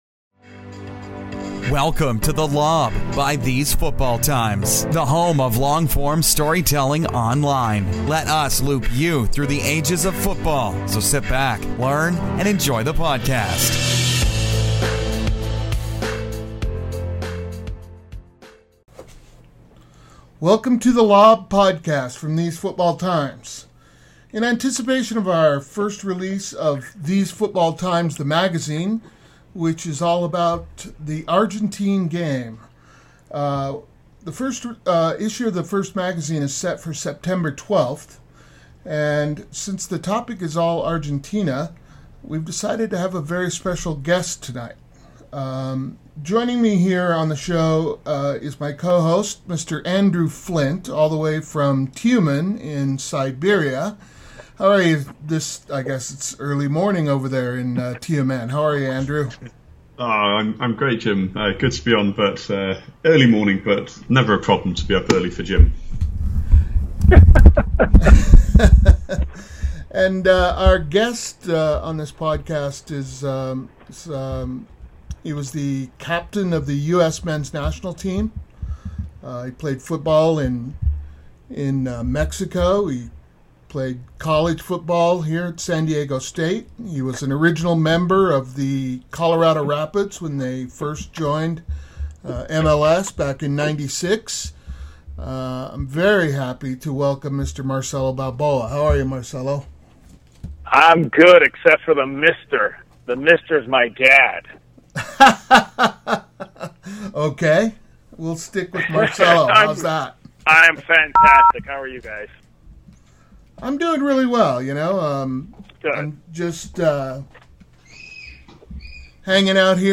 A chat with US legend Marcelo Balboa